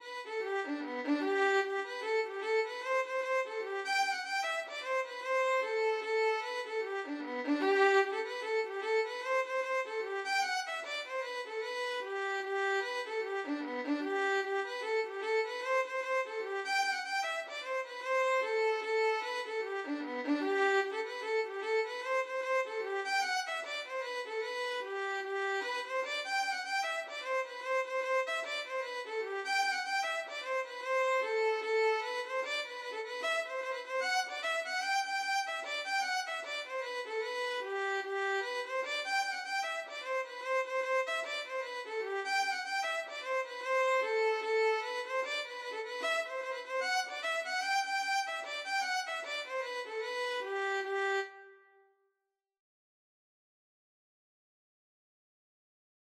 Traditional Trad. The Glasgow (Irish Hornpipe) Violin version
G major (Sounding Pitch) (View more G major Music for Violin )
4/4 (View more 4/4 Music)
Violin  (View more Intermediate Violin Music)
Traditional (View more Traditional Violin Music)
the_glasgow_ON1648_VLN.mp3